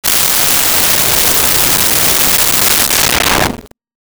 Alien Woosh 03
Alien Woosh 03.wav